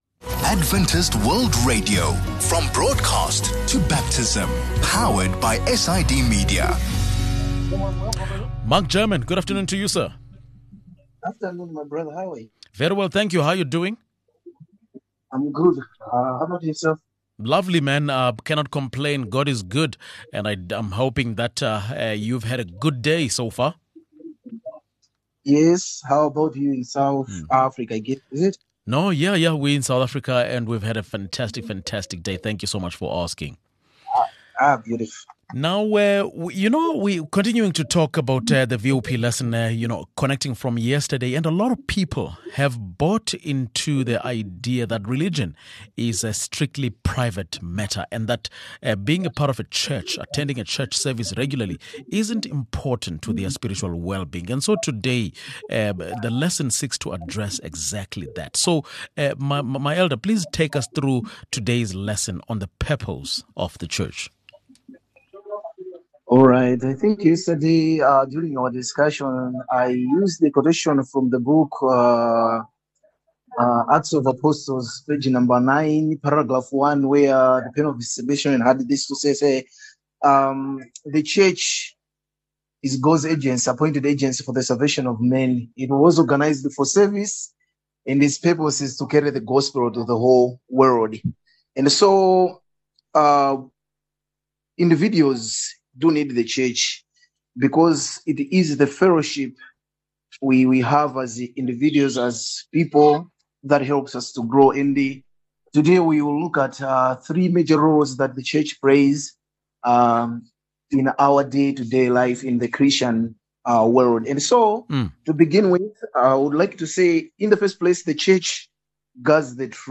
A lot of people have bought into the idea that religion is a strictly private matter, and that being a part of a church, attending church service regularly, isn’t’ important to their spiritual wellbeing. Today’s lesson seeks to address that.